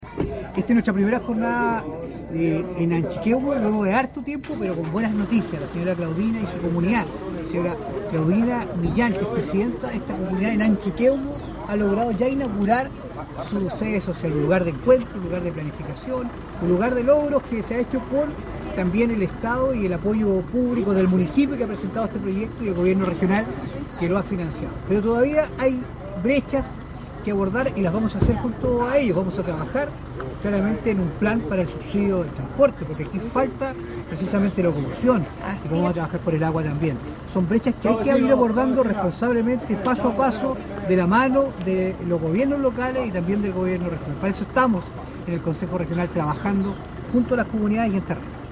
El consejero regional por Osorno, Francisco Reyes, enfatizó la importancia de esta obra para la comunidad pues fue una espera de mucho tiempo y se logró gracias trabajo de sus dirigentes.